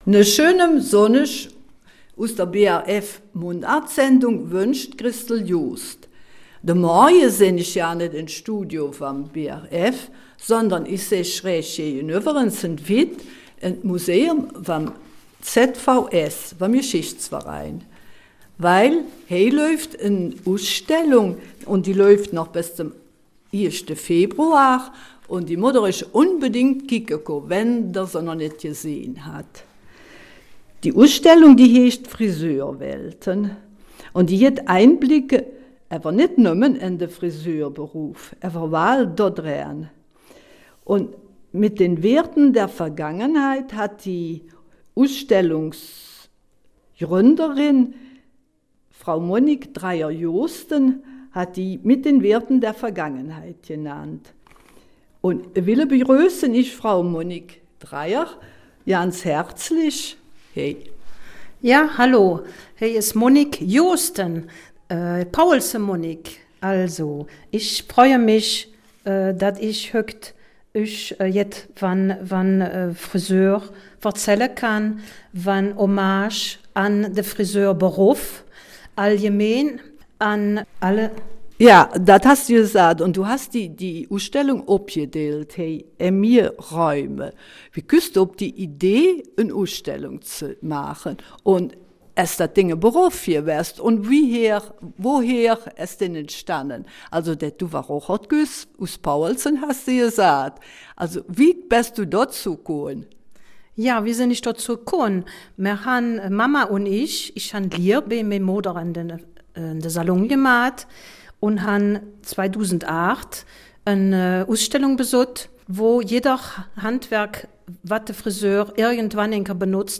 Eifeler Mundart: ''Friseurwelten'' im ZVS-Museum